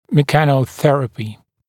[mɪˌkænə’θerəpɪ][миˌкэнэ’сэрэпи]механотерапия, терапия с применением механических методов лечения